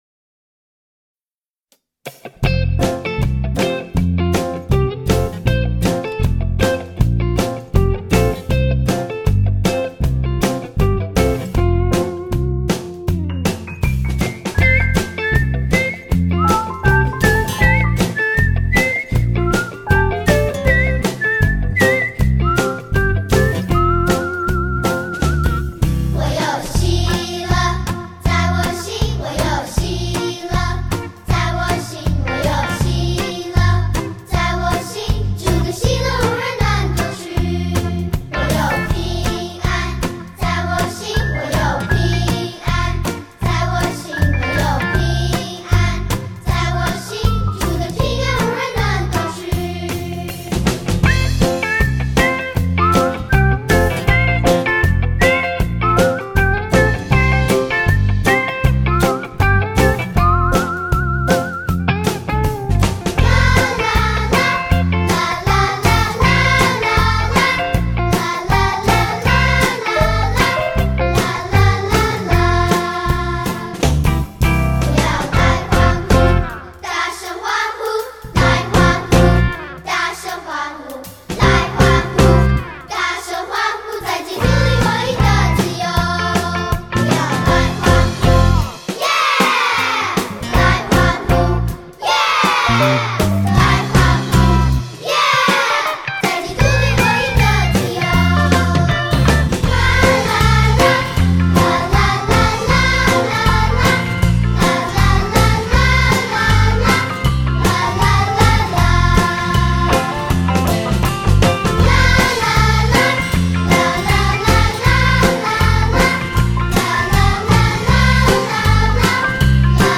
动作跟唱 | 我有喜乐 (视频+音频)